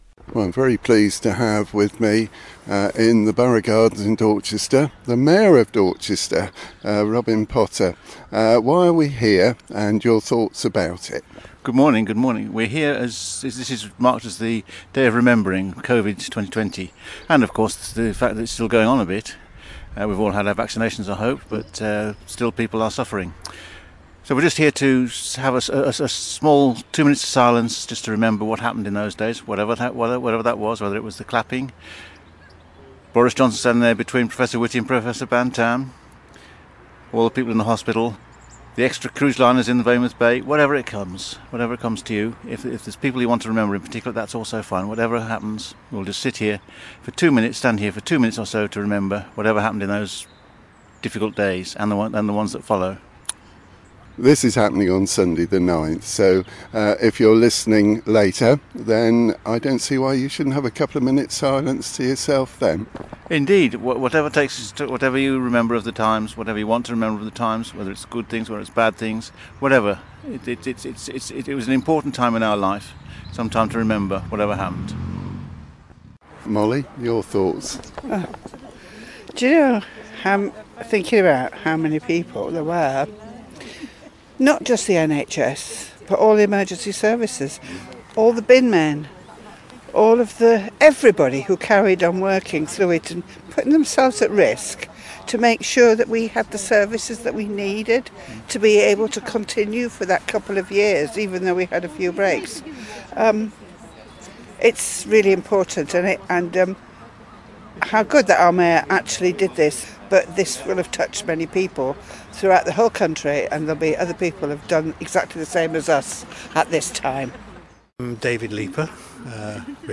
On Sunday 9th March 2025 Dorchester Town Council marked National Covid Day at the Bandstand in the Borough Gardens with a quiet moment of reflection. Members of the local community  were invited to join the Mayor and others to share their experiences.